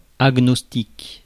Ääntäminen
Synonyymit areligieux incroyant Ääntäminen France: IPA: /a.ɡnɔs.tik/ Haettu sana löytyi näillä lähdekielillä: ranska Käännöksiä ei löytynyt valitulle kohdekielelle.